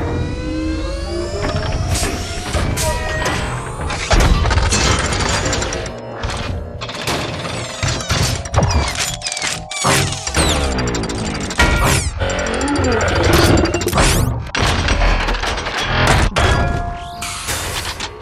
На этой странице собраны звуки из вселенной Трансформеров: эффекты трансформации, футуристические боевые режимы, голоса известных автоботов и десептиконов.
Звук трансформации трансформера в машину